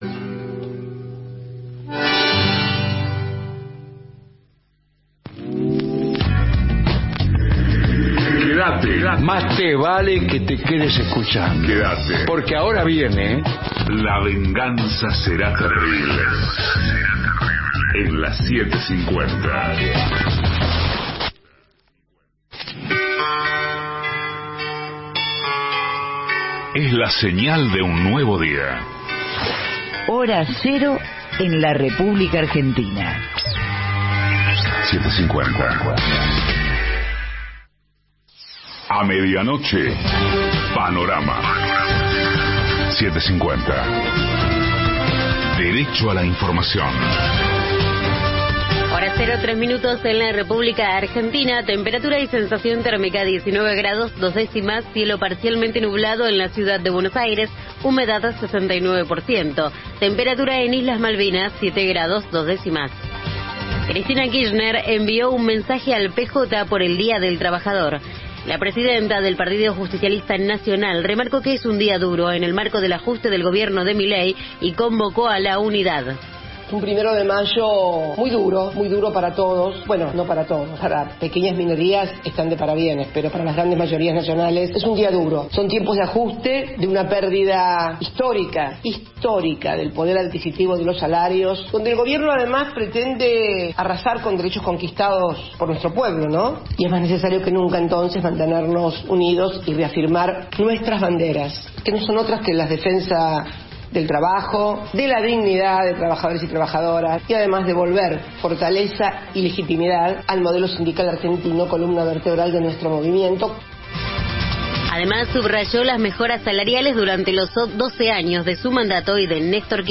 La Venganza Será Terrible: todo el año festejando los 40 años Estudios AM 750 Alejandro Dolina, Patricio Barton, Gillespi Introducción • Entrada[0:09:00]( play 0:09:00) Segmento Inicial • Cosas